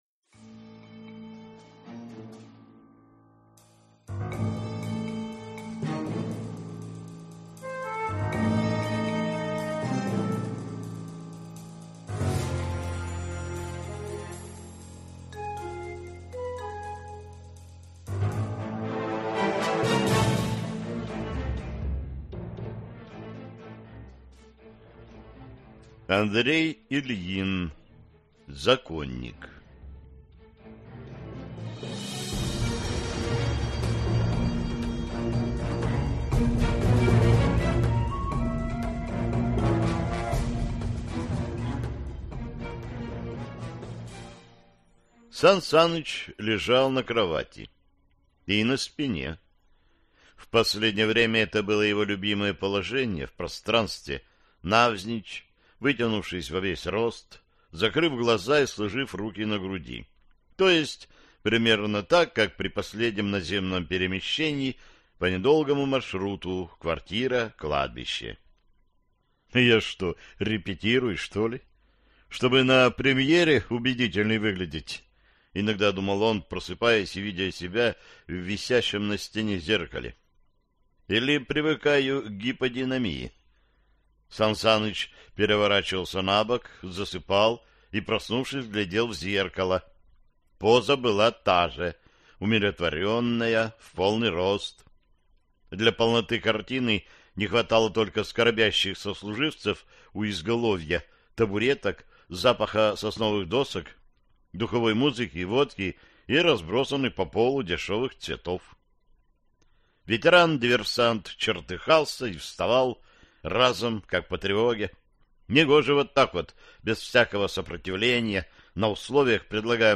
Аудиокнига Законник | Библиотека аудиокниг